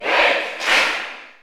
Category: Crowd cheers (SSBU) You cannot overwrite this file.